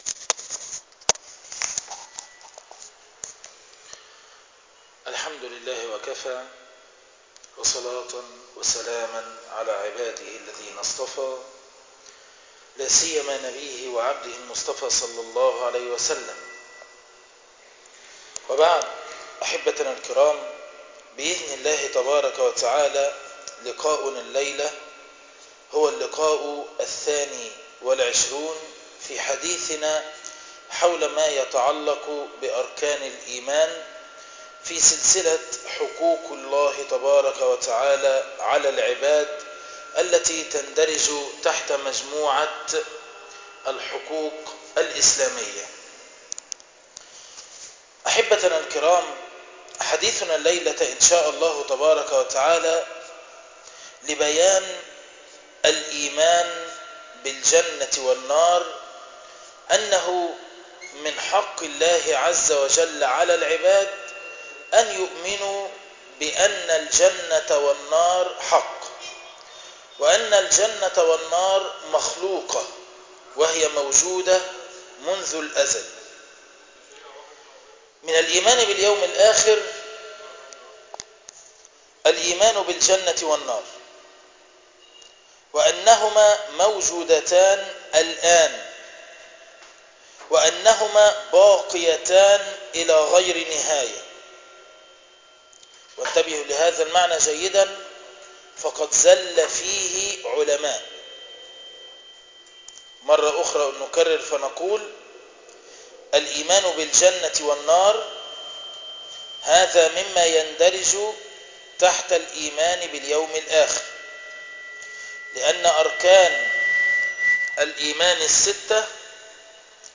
عنوان المادة حق الله على العباد الدرس الثانى والعشرون تاريخ التحميل الخميس 28 فبراير 2013 مـ حجم المادة 24.32 ميجا بايت عدد الزيارات 919 زيارة عدد مرات الحفظ 320 مرة إستماع المادة حفظ المادة اضف تعليقك أرسل لصديق